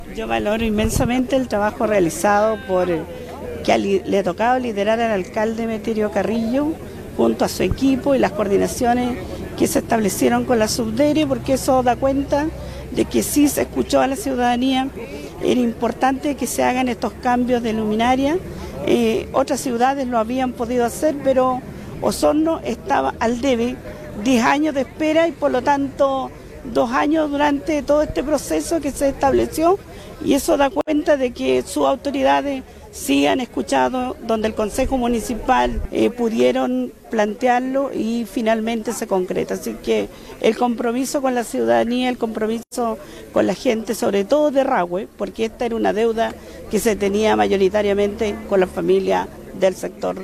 Este martes, se dio inicio al recambio de 15.872 luminarias en el radio urbano de Osorno, en un acto celebrado en la Plaza España de Rahue Alto.
La Diputada Emilia Nuyado, indicó que luego de años de espera se escuchó la necesidad de los vecinos y vecinas del sector de Rahue, quienes finalmente fueron escuchados por las autoridades.